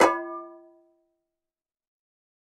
Звуки сковородки
глухое звучание от удара по сковороде